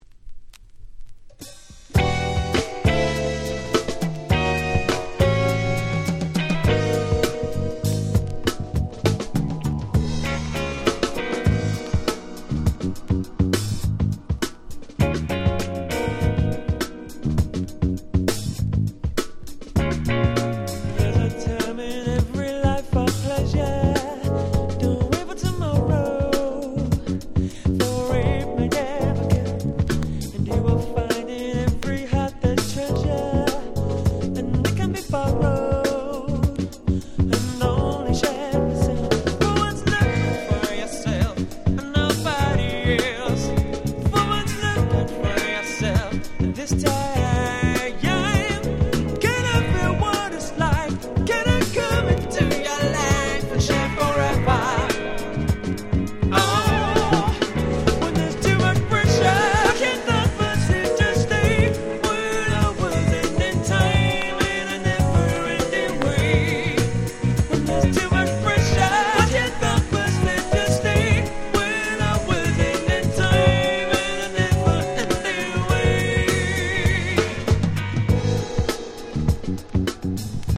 95' Very Nice UK Soull / Acid Jazz !!
全編爽やかですがどこか土の香りがするEarthyなUK Soulナンバーがてんこ盛り。